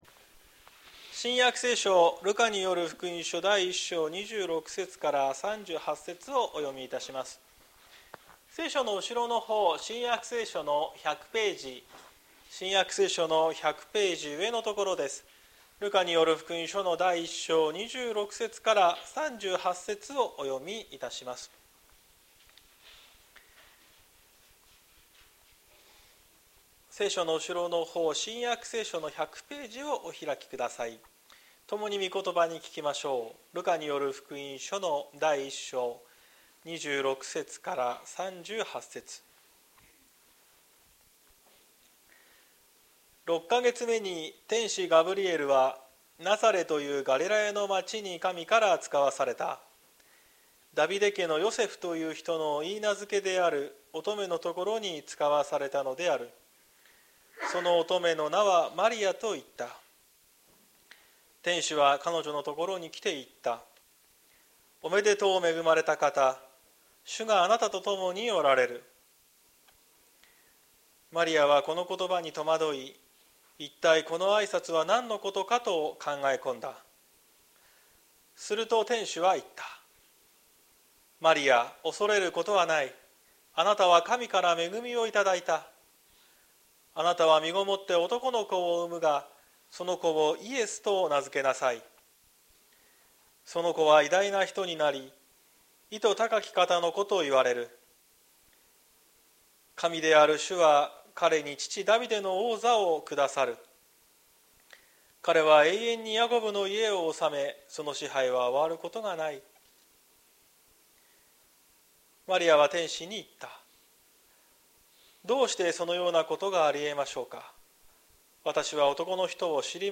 2024年12月08日朝の礼拝「恐れを締め出す喜び」綱島教会
綱島教会。説教アーカイブ。